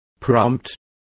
Also find out how sugerencia is pronounced correctly.